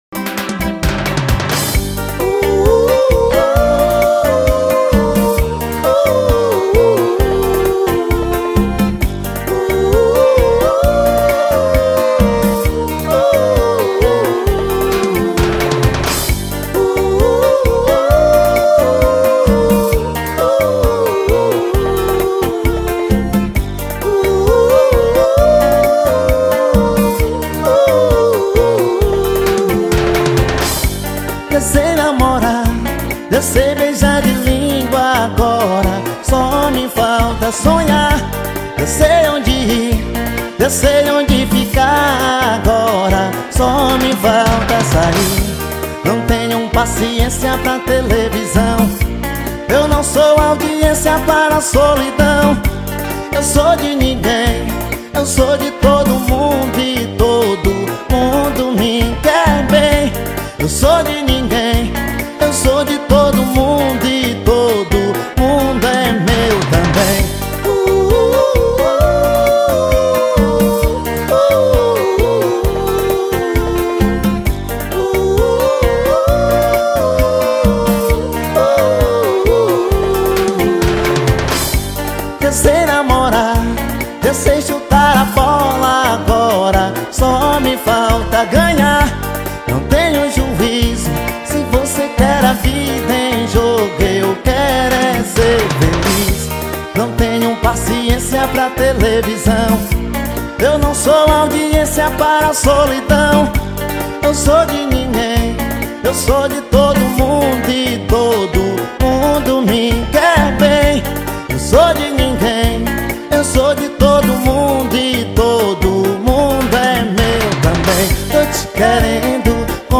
2024-06-26 18:39:58 Gênero: MPB Views